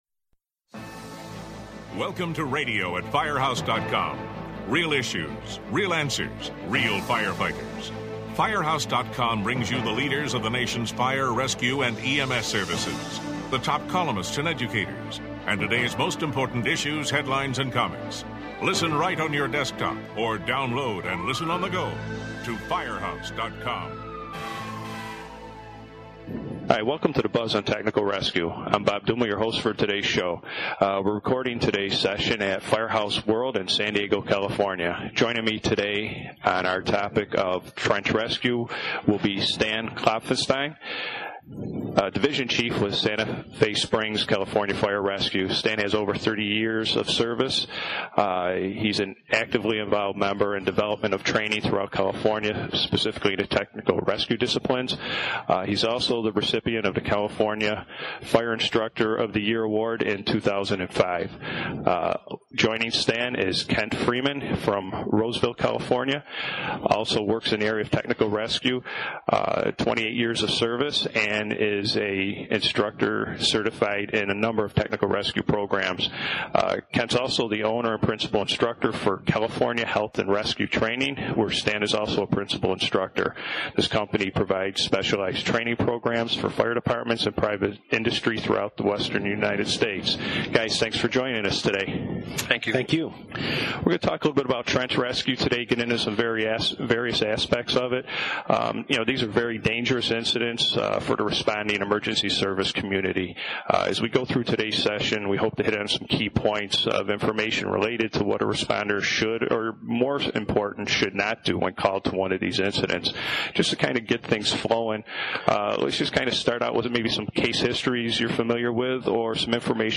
This podcast was recorded at Firehouse World earlier this week.